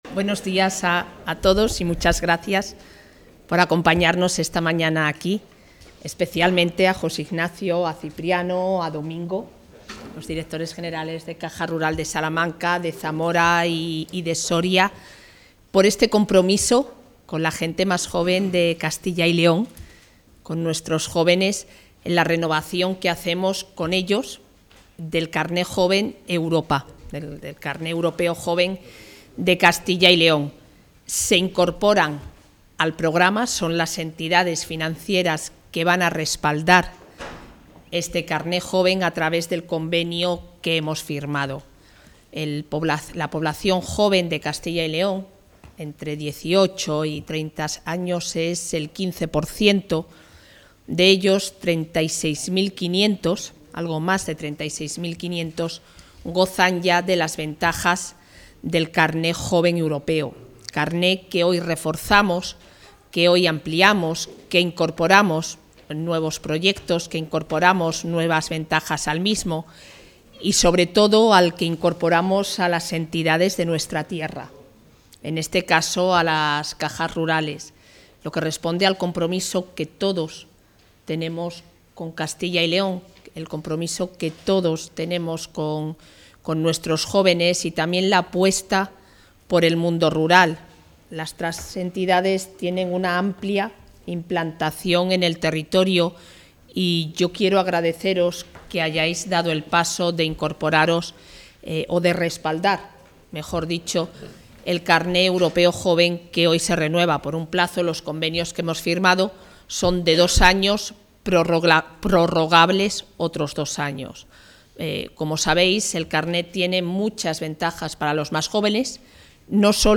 Intervención de la vicepresidenta.